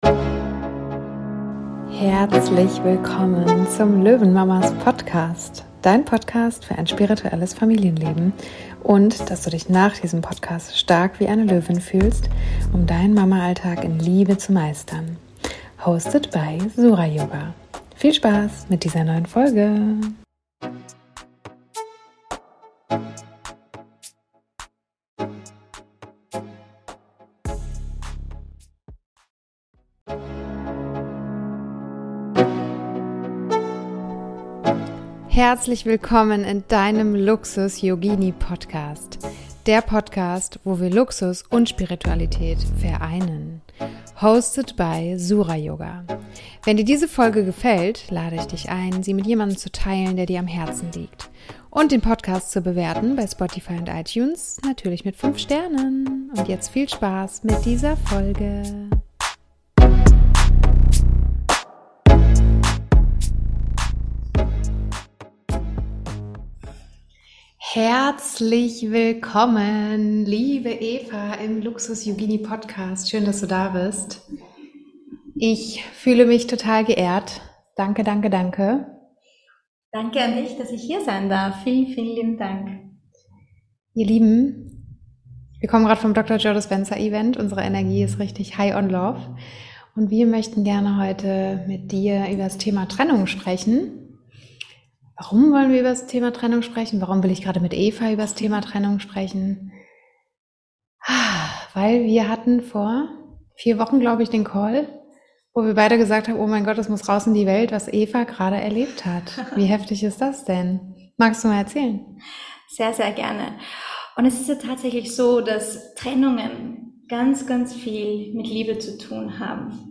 Danke für dieses wundervolle Interview.